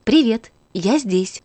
Звуки робота пылесоса